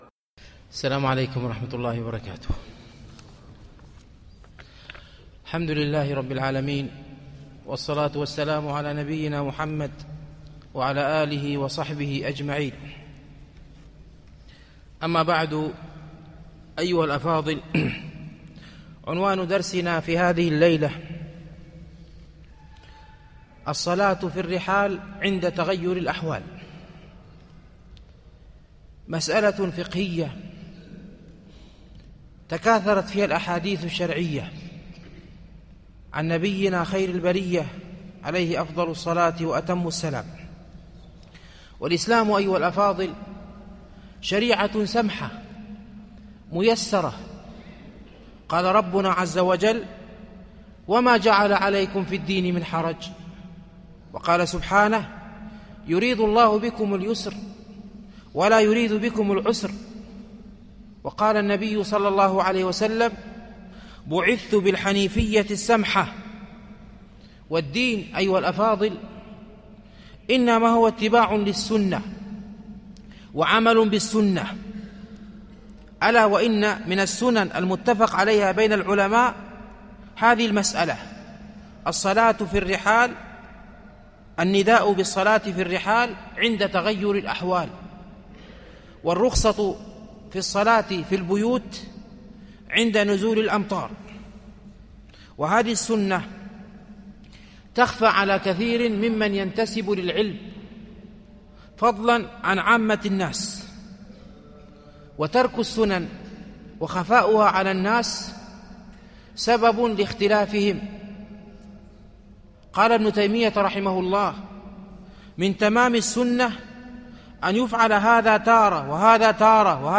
الألبوم: محاضرات